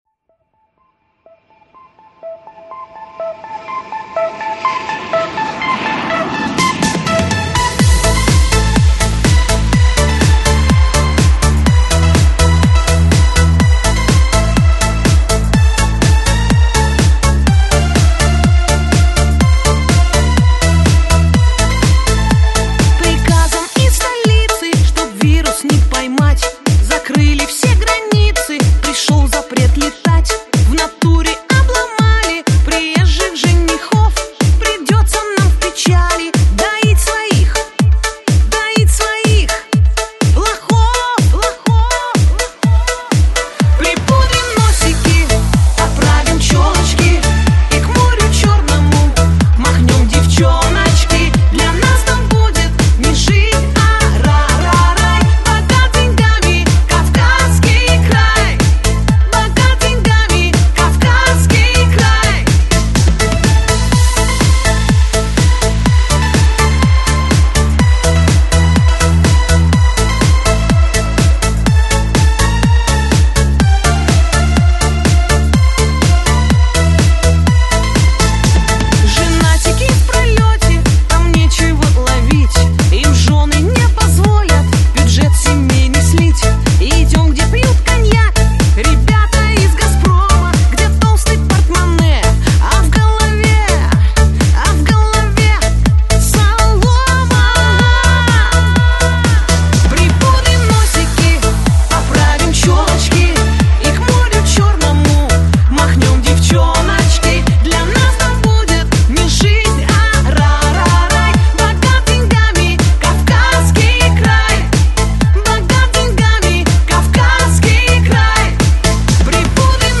Жанр: Chanson